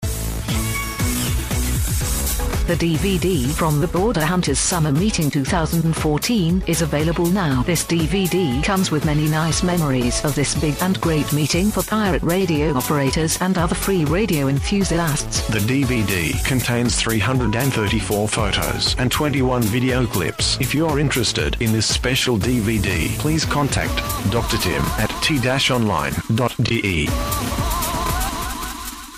Cupid Radio is on 6300 Khz at 1442 Utc with SINPO 34433. Audio not 100%.